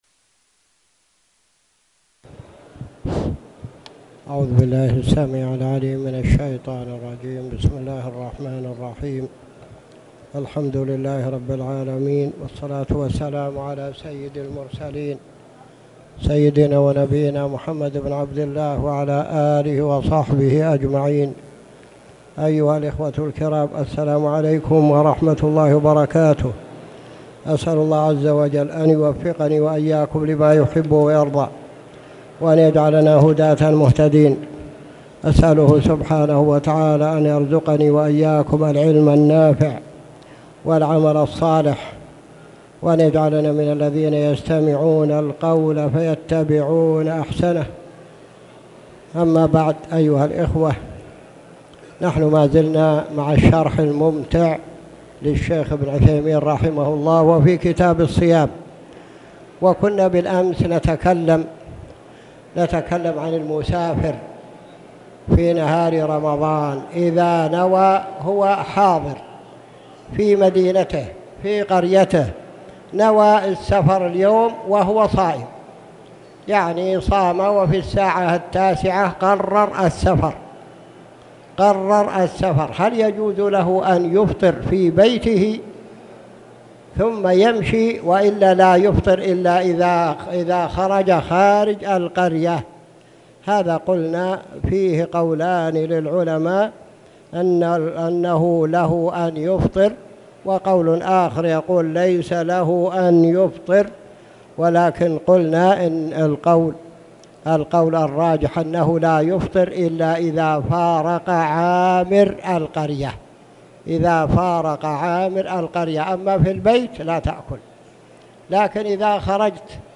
تاريخ النشر ٢٢ رجب ١٤٣٨ هـ المكان: المسجد الحرام الشيخ